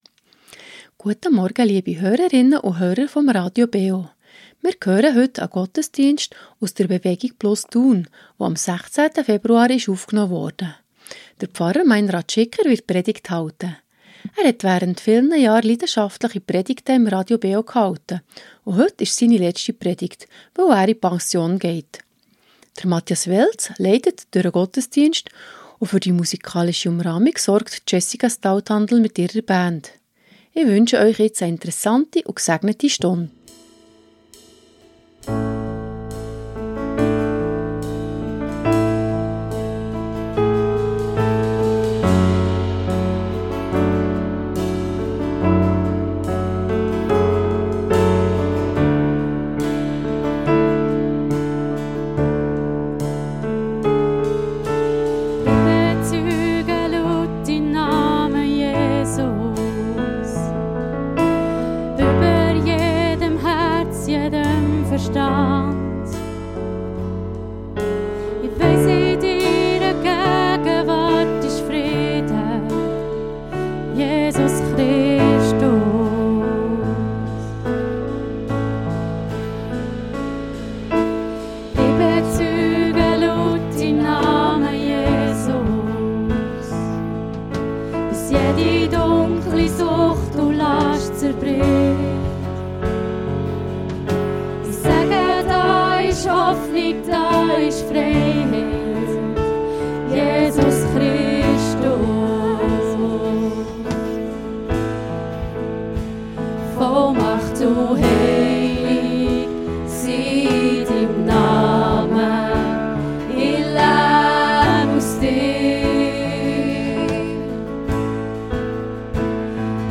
BewegungPlus Thun ~ Gottesdienst auf Radio BeO Podcast